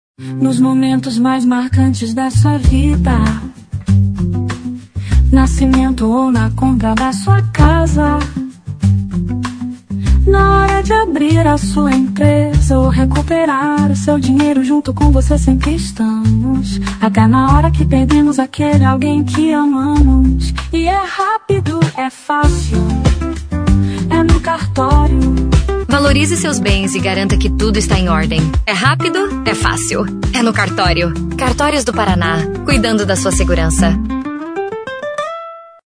Jingle
Jingle reduzido